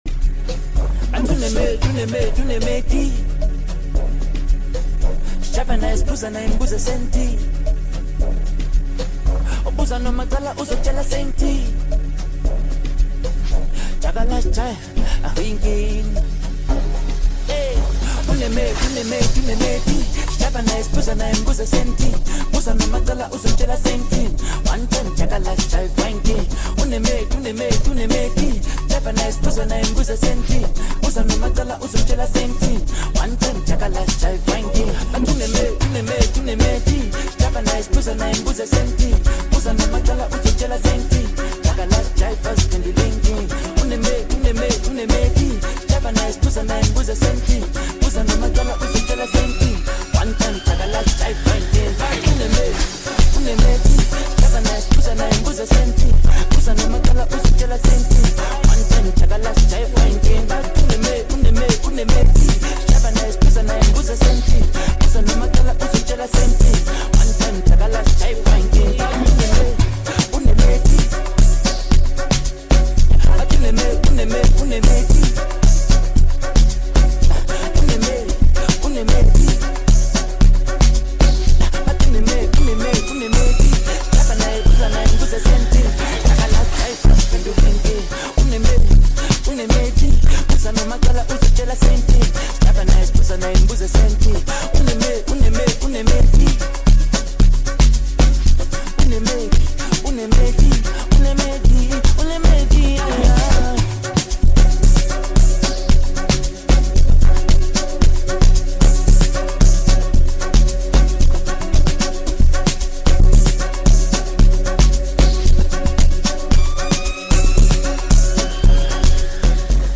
known for his smooth vocals and soulful delivery
a talented producer and DJ
a perfect blend of Afrobeat and Amapiano